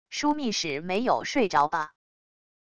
枢密使没有睡着吧wav音频生成系统WAV Audio Player